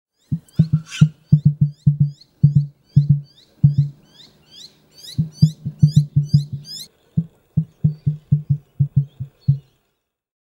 Звуки включают крики, шум шагов и другие характерные для эму аудиоэффекты.
Звук пения птицы и стук эму